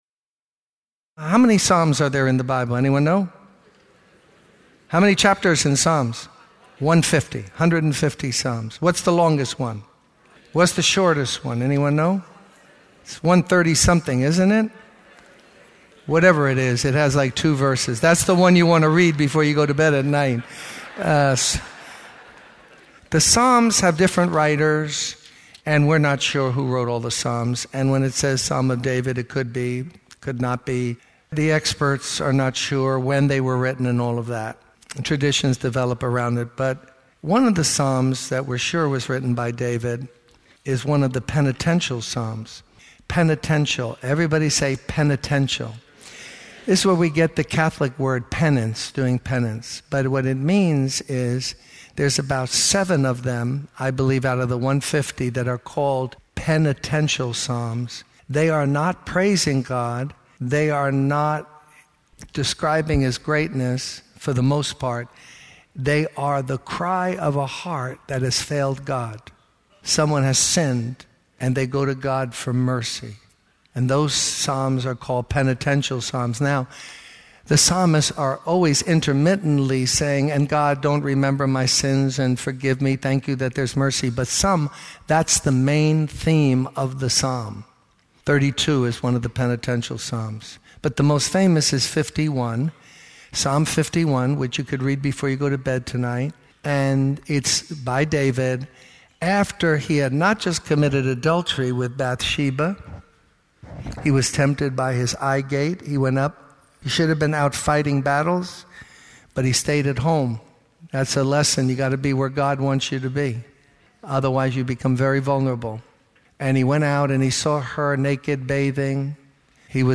In this sermon, the speaker emphasizes the importance of having a steadfast and willing spirit in our relationship with God.